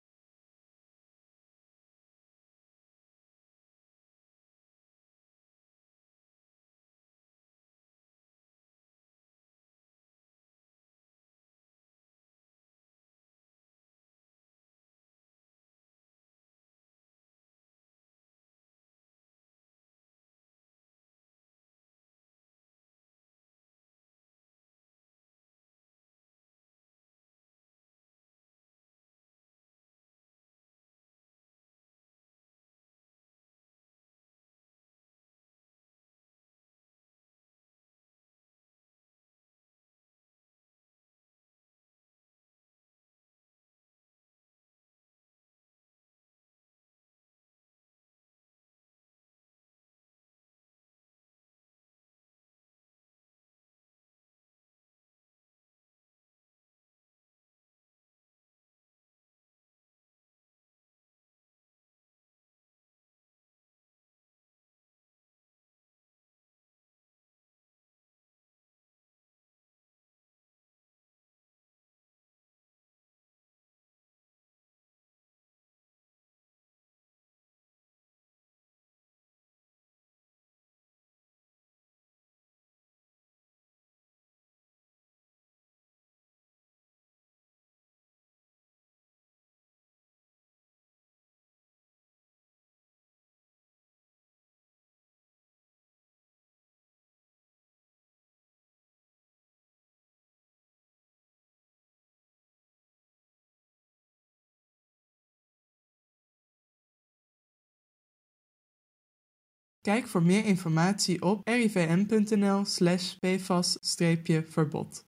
Voice-over tekst: